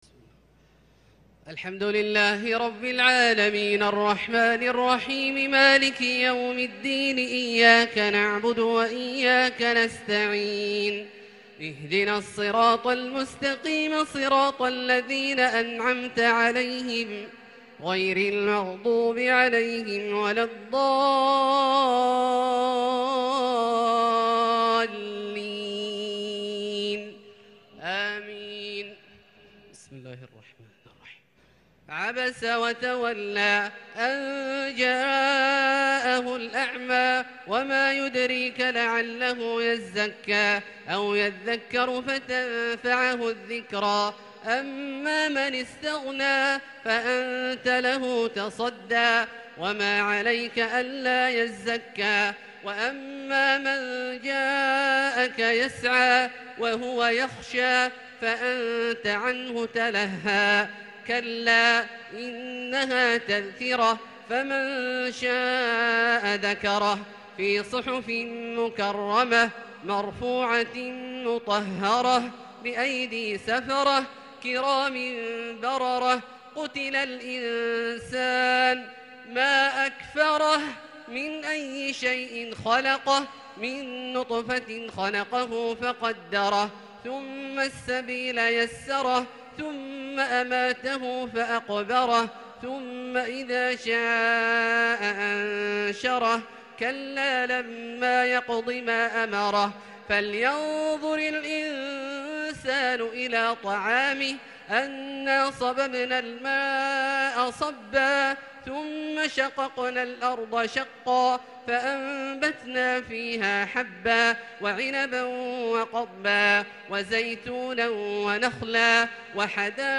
صلاة التهجد | ليلة 29 رمضان 1442ھ | من سورة عبس إلى سورة الزلزلة | tahajud prayer The 29rd night of Ramadan 1442H | > تراويح الحرم المكي عام 1442 🕋 > التراويح - تلاوات الحرمين